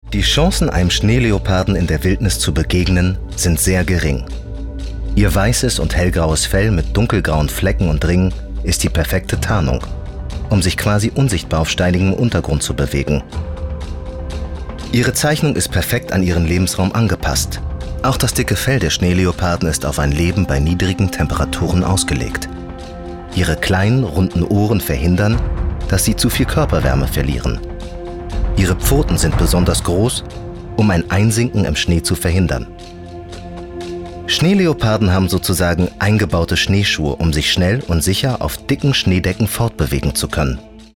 Synchronausschnitt